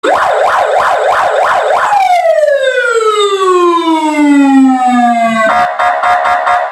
Звуки полицейской крякалки
Звук крякалки полицейской сирены с кряколкой для розыгрыша